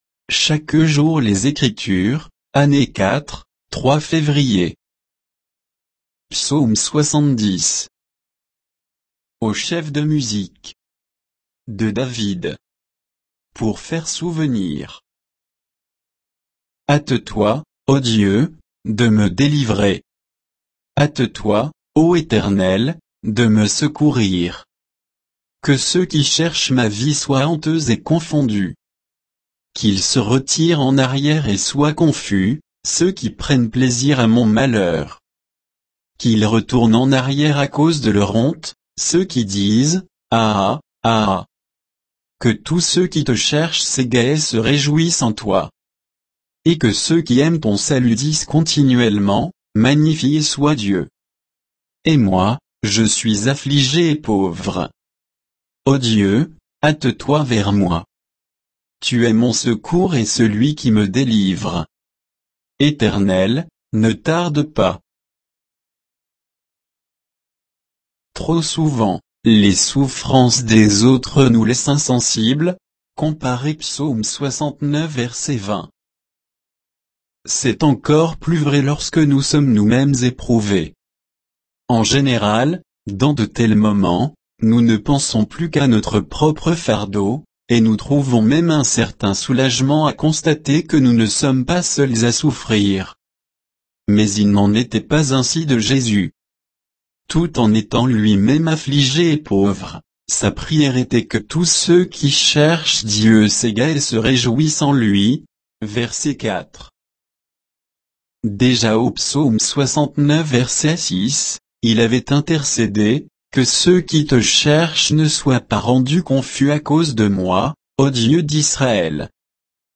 Méditation quoditienne de Chaque jour les Écritures sur Psaume 70